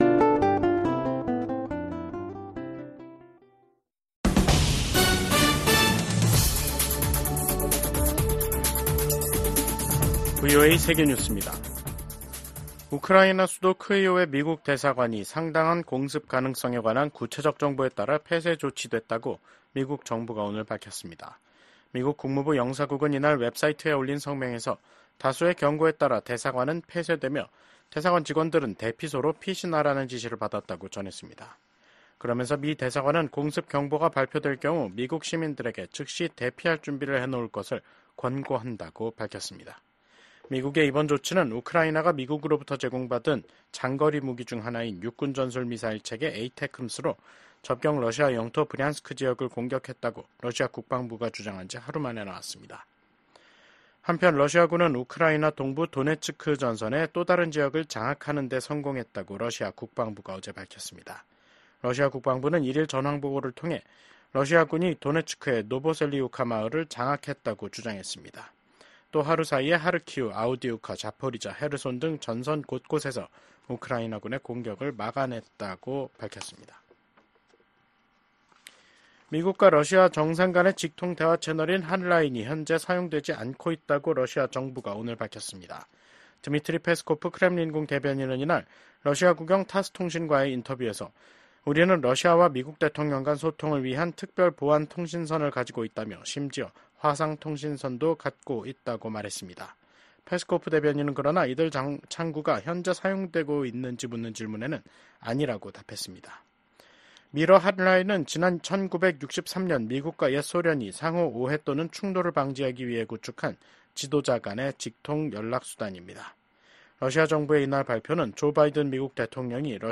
VOA 한국어 간판 뉴스 프로그램 '뉴스 투데이', 2024년 11월 20일 2부 방송입니다. 한국 정보 당국은 북한 군이 러시아 군에 배속돼 우크라이나 전쟁에 참여하고 있다고 밝혔습니다. 미국 국방부는 러시아의 우크라이나 침략 전쟁에 참전하는 북한군은 정당한 합법적인 공격 목표가 될 것이라고 재차 경고했습니다. 미국의 우크라이나 전문가들은 북한군 파병이 러시아-우크라이나 전쟁에 미치는 영향이 제한적인 것이라고 전망했습니다.